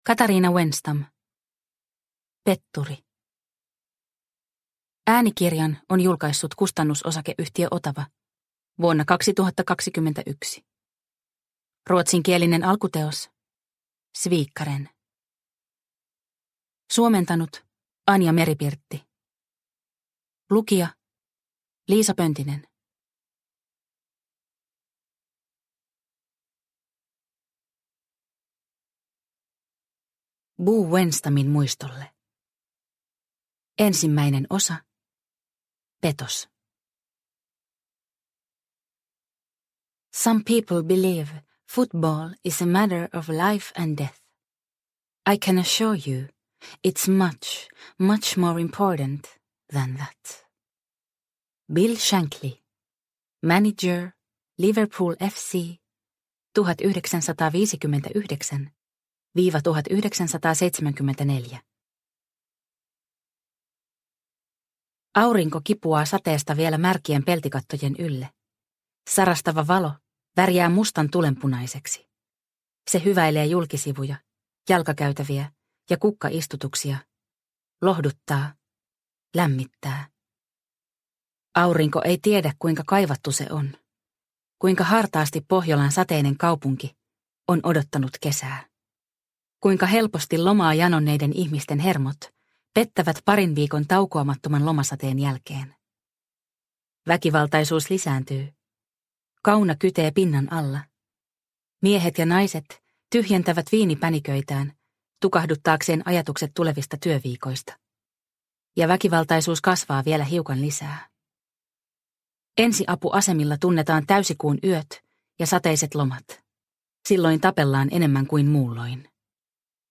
Petturi – Ljudbok – Laddas ner